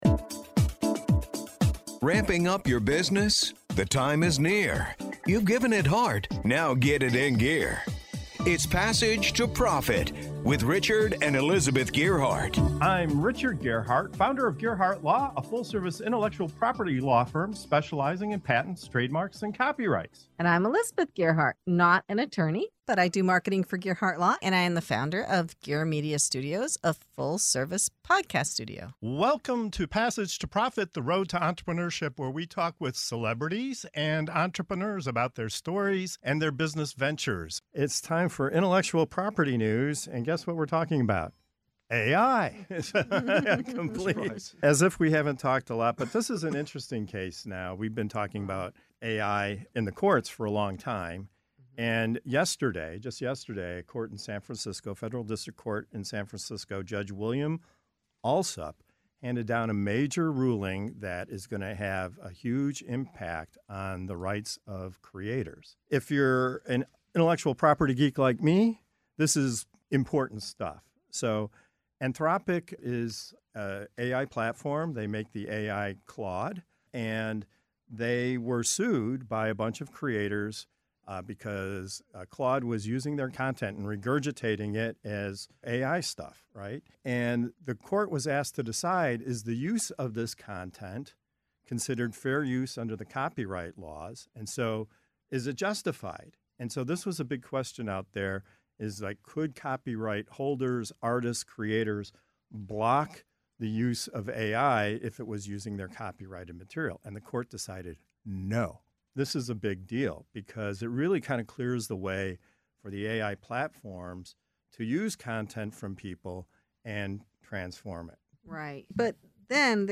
The panel unpacks what it means for your IP, your business model, and the fight for ethical AI.